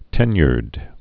(tĕnyərd, -yrd)